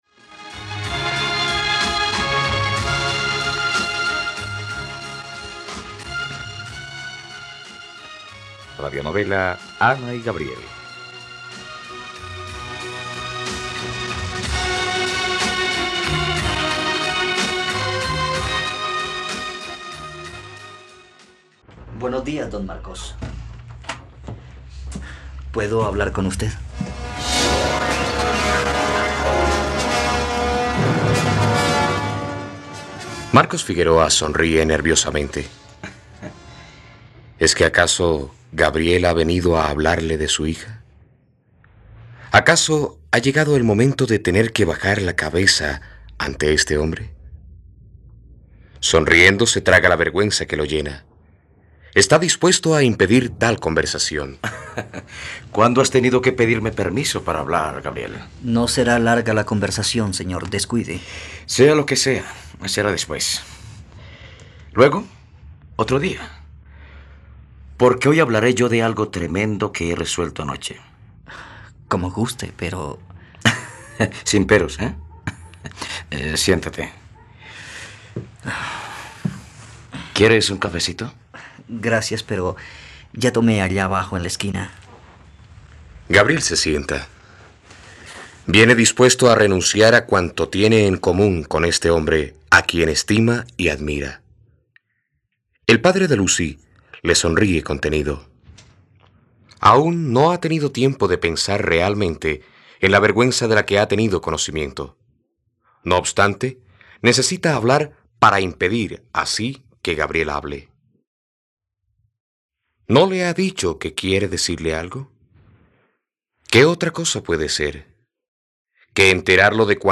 Ana y Gabriel - Radionovela, capítulo 23 | RTVCPlay